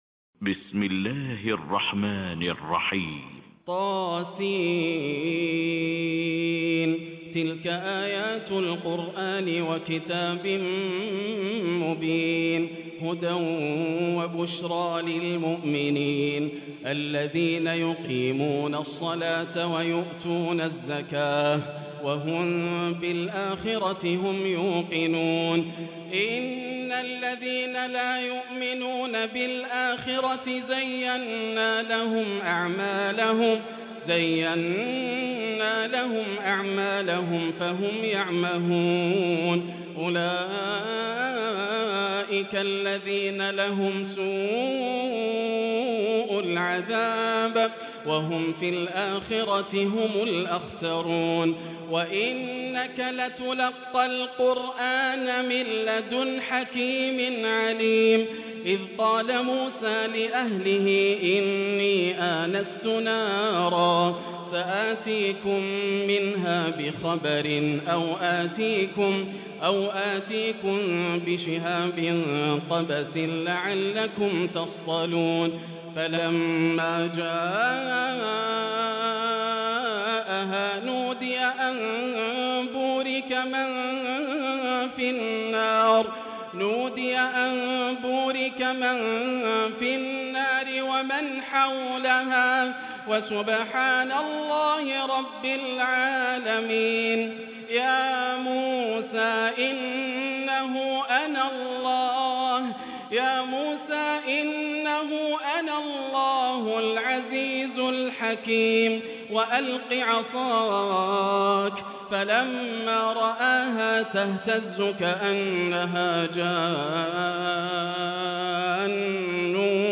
Tarawih prayer from the holy Mosque